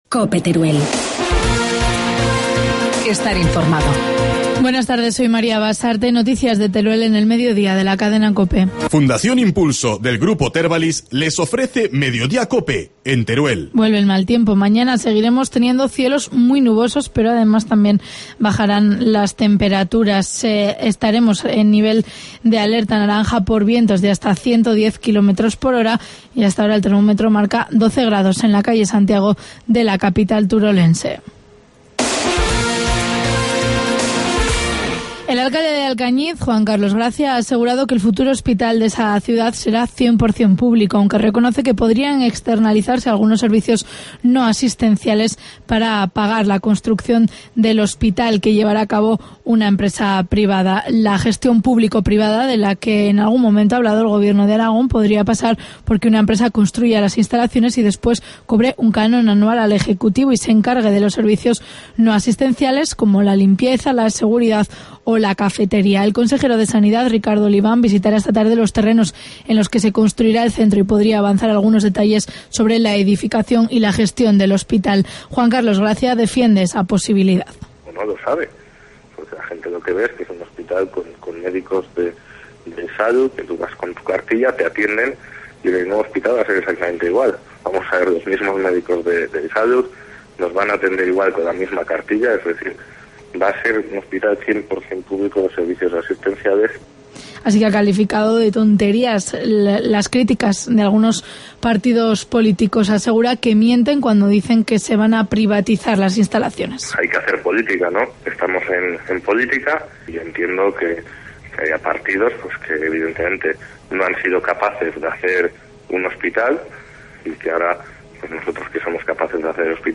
Informativo mediodía, martes 12 de marzo